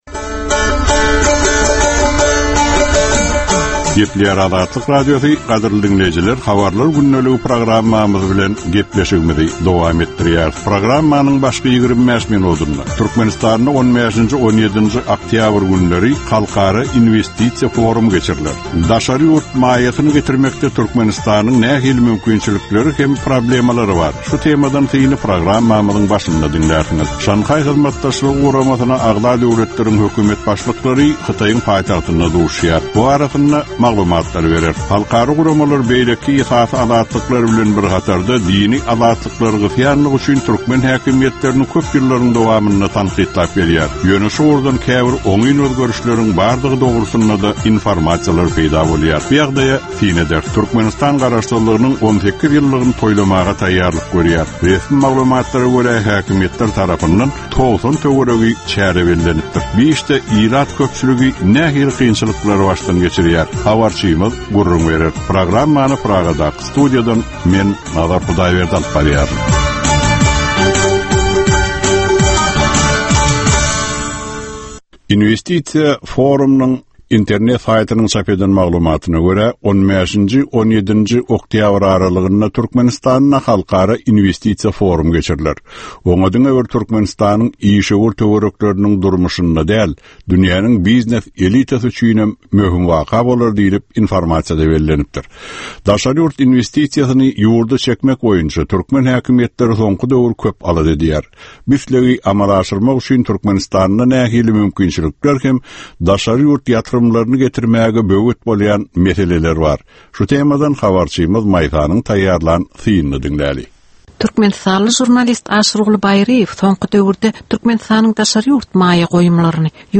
Türkmenistandaky we halkara arenasyndaky soňky möhüm wakalar we meseleler barada ýörite informasion-analitiki programma. Bu programmada soňky möhüm wakalar we meseleler barada ginişleýin maglumatlar, analizler, synlar, makalalar, söhbetdeşlikler, reportažlar, kommentariýalar we diskussiýalar berilýär.